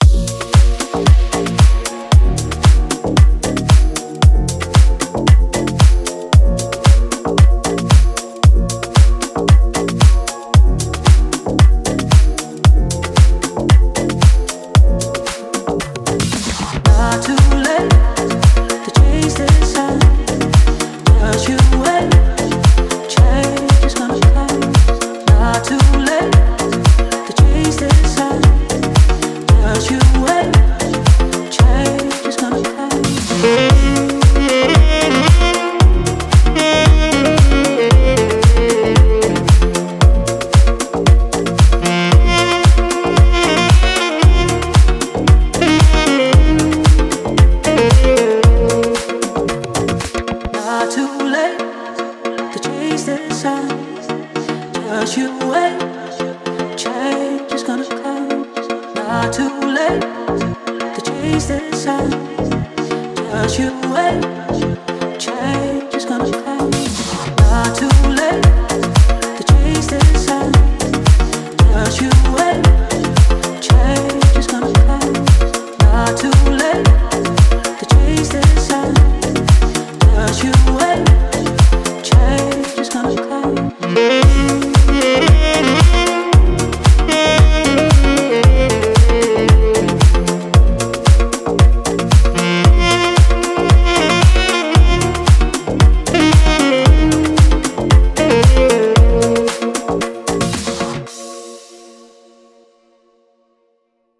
1887_Deep_House_2025